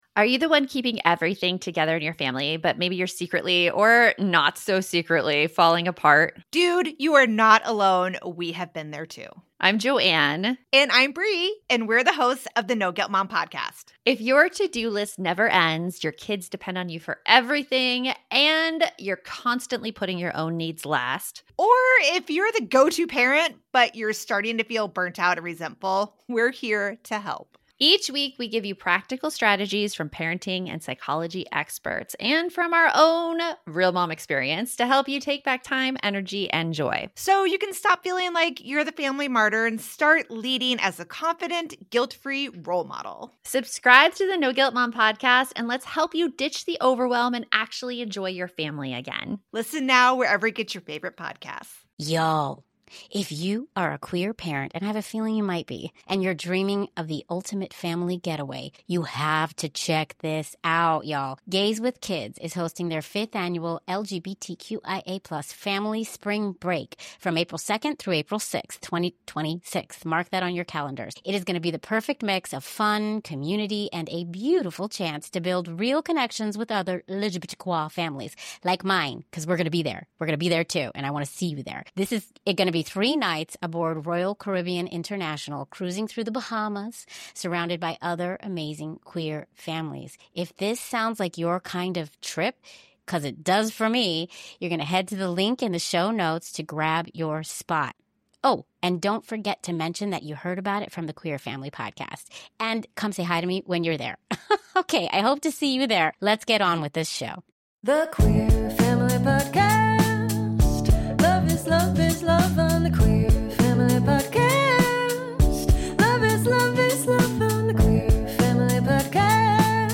The Queer Family Podcast brings you weekly interviews with LGBTQIA+ guests exploring how they made their families and how they navigate a world that wasn’t necessarily built for them.